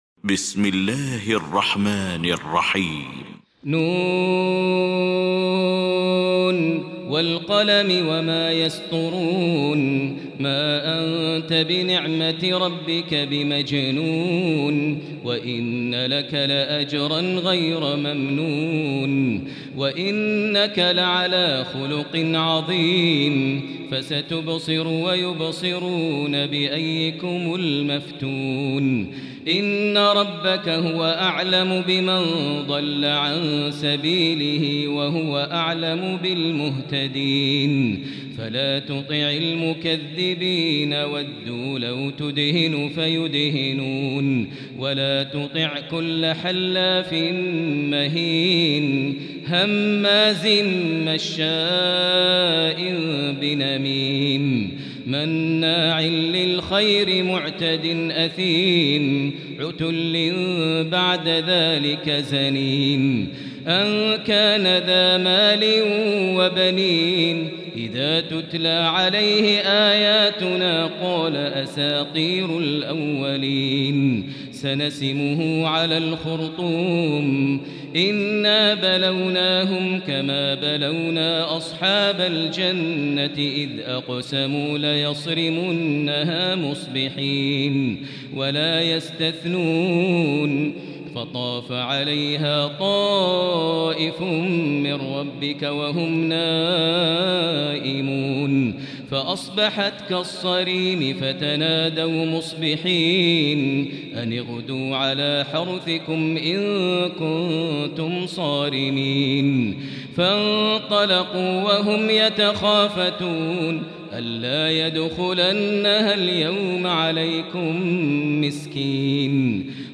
المكان: المسجد الحرام الشيخ: فضيلة الشيخ ماهر المعيقلي فضيلة الشيخ ماهر المعيقلي القلم